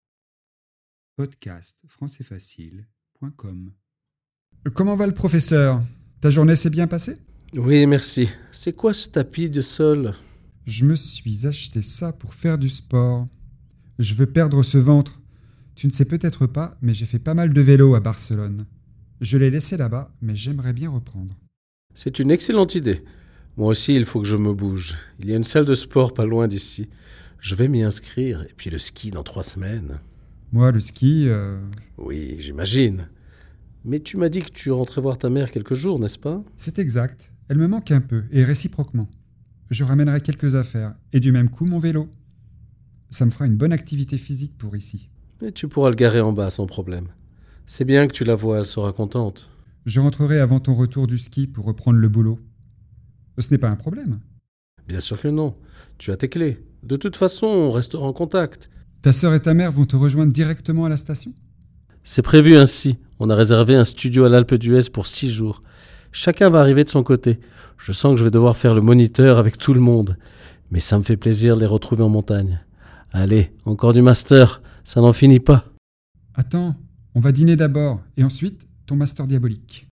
🔷 DIALOGUE :